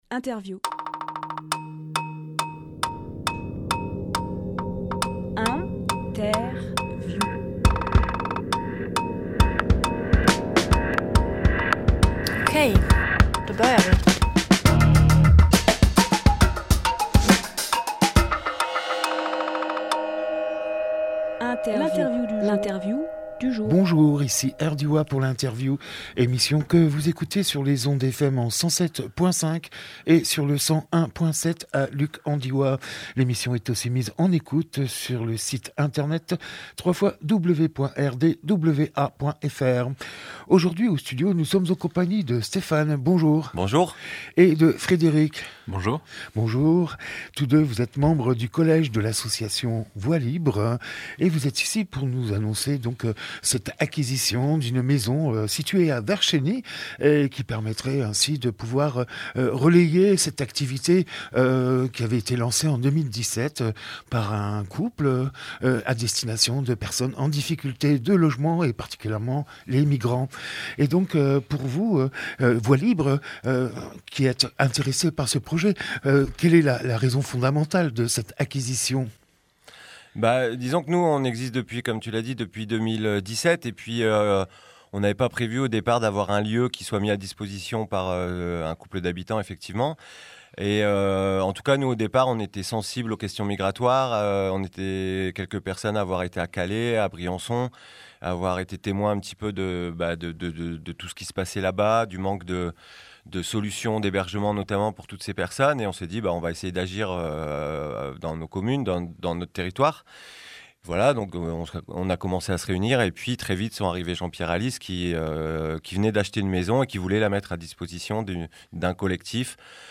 Emission - Interview Projet d’achat de la maison d’Elisa à Vercheny Publié le 31 mars 2023 Partager sur…
30.03.23 Lieu : Studio RDWA Durée